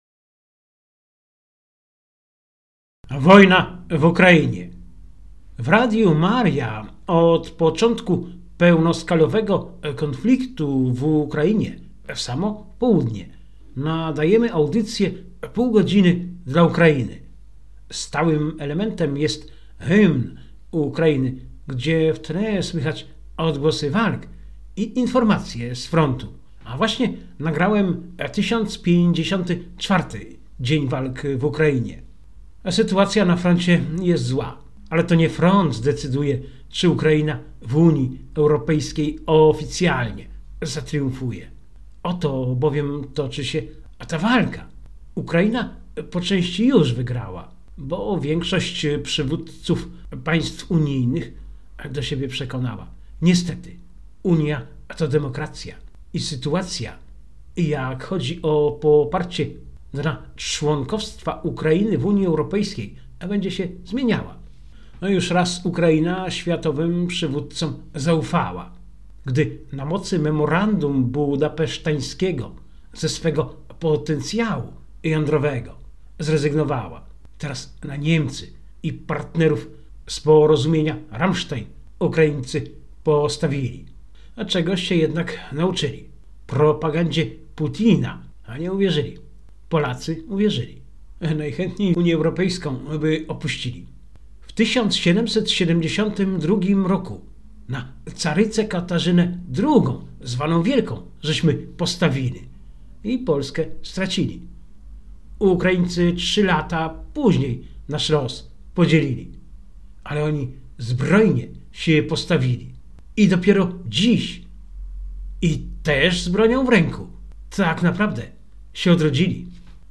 W Radiu Maria od początku pełnoskalowego konfliktu w Ukrainie, w samo południe nadajemy audycję „Pół godziny dla Ukrainy”. Stałym elementem jest hymn Ukrainy, gdzie w tle słychać odgłosy walk i informacje z frontu.